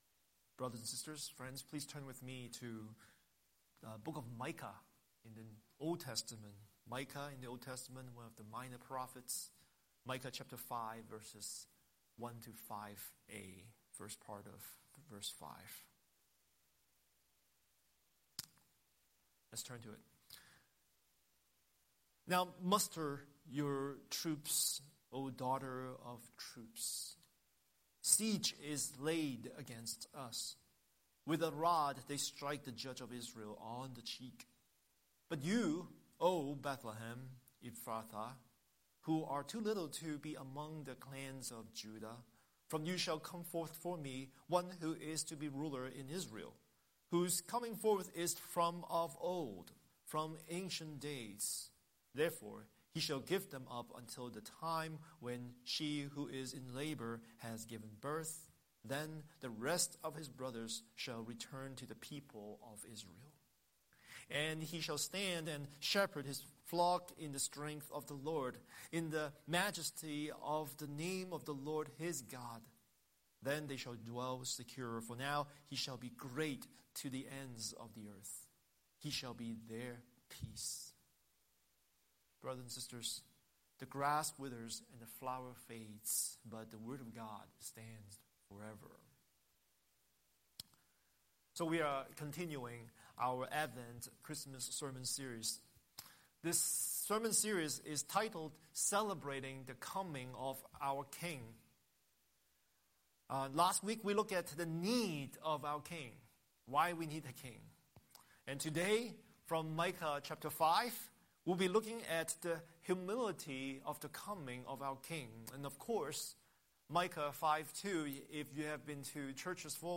Scripture: Micah 5:1–5a Series: Sunday Sermon